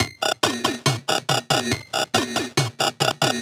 Gamer World Drum Loop 3.wav